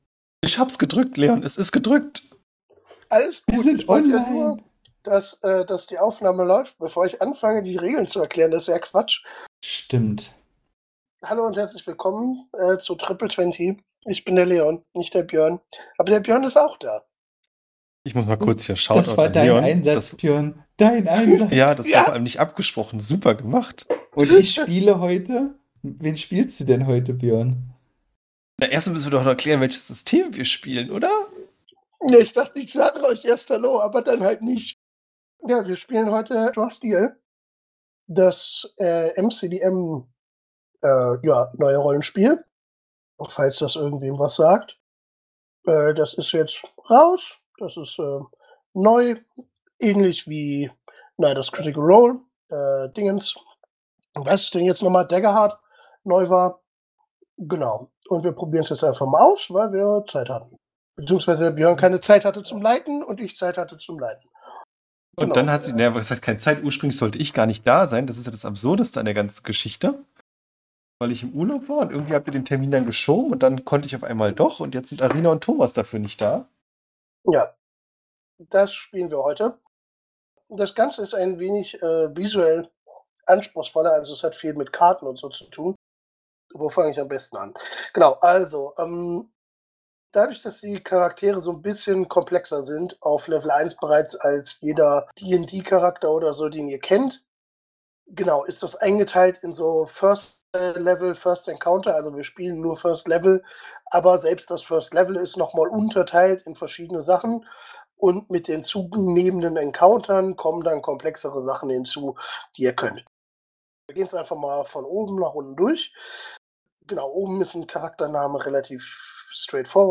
Die Episode war qualitativ ein Debakel.
So, aber positiv gesehen: Es hat mich zwar etliche Stunden gekostet, aber ich finde, das Ergebnis ist ertragbar.